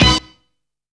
TOP STAB.wav